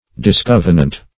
\Dis*cov"e*nant\
discovenant.mp3